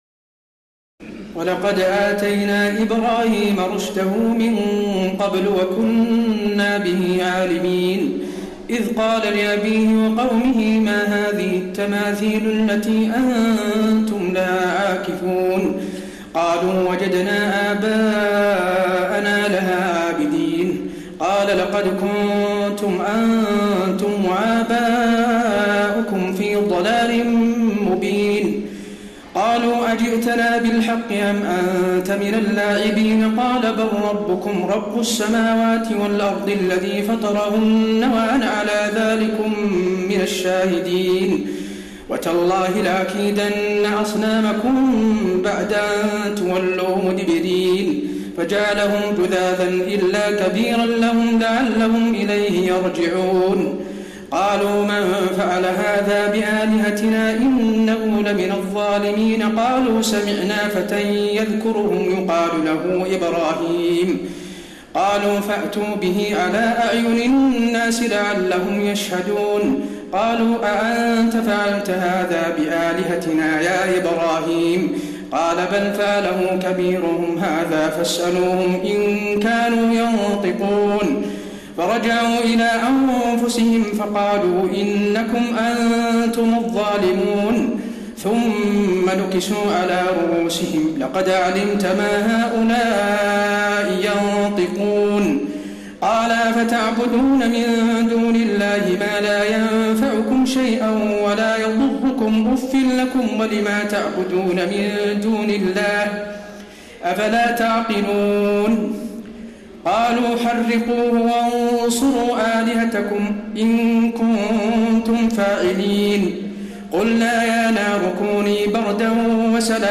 تراويح الليلة السادسة عشر رمضان 1432هـ من سورتي الأنبياء (51-112) الحج (1-59) Taraweeh 16 st night Ramadan 1432H from Surah Al-Anbiyaa and Al-Hajj > تراويح الحرم النبوي عام 1432 🕌 > التراويح - تلاوات الحرمين